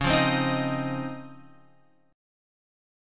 C# Major 9th